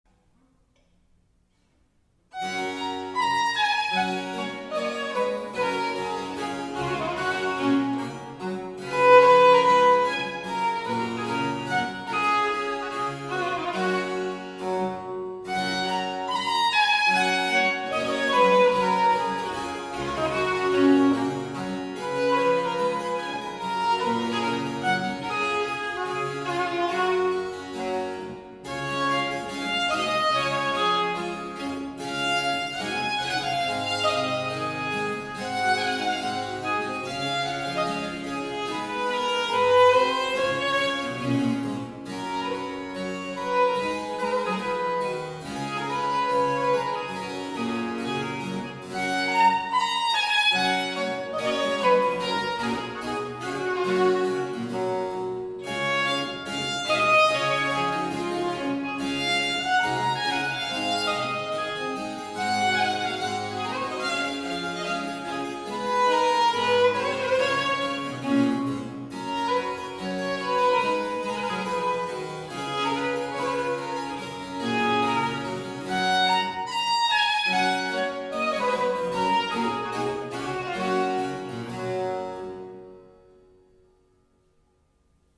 The music is scored for violins I/II in unison, basso, and horns.
(MIDI orchestral version)
peintre-1-slowed-by-10-percent.mp3